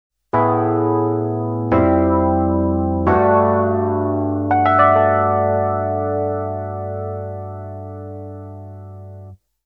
Die Besonderheit dieses Pedals liegt darin, daß es 2 Modulationseinheiten enthält, die gegenläufig arbeiten. Hierdurch entsteht ein Chorus-artiger Detune-Effekt ohne das übliche ‚wabern‘ herkömmlicher Choruspedale.